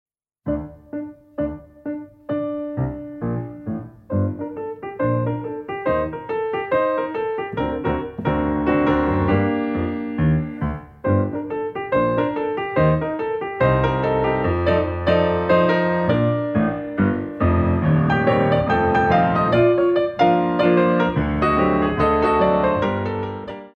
Compositions for Ballet Class
2 eme Dégagés